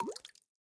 drip_water_cauldron1.ogg